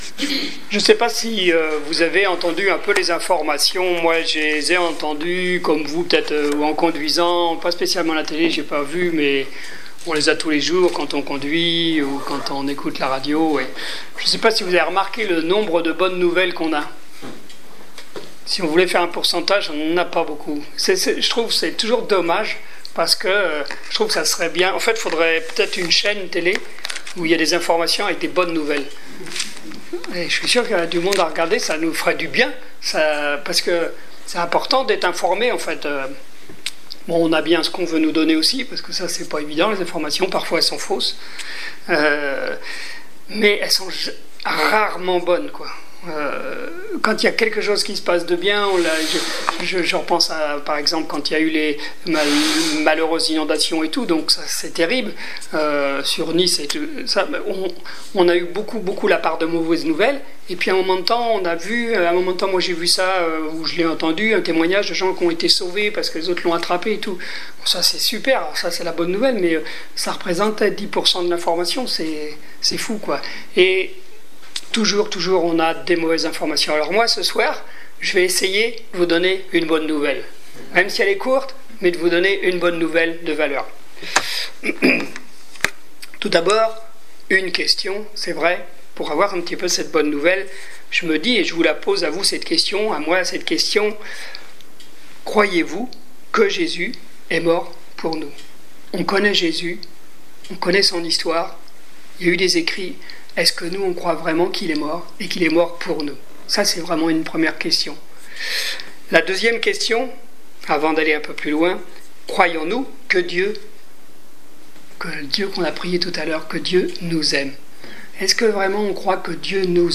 Étude biblique du 14 octobre 2015